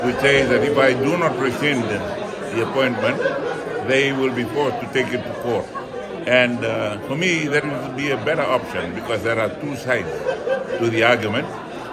Speaking to FBC News in Canberra, Rabuka says he respects the view of the Fiji Law Society but he will stick with his decision.